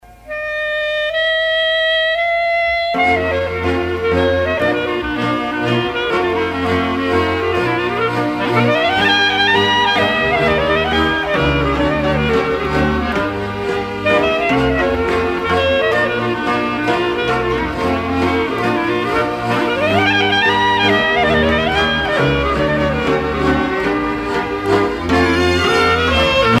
danse : învîrtita (Roumanie)
Pièce musicale éditée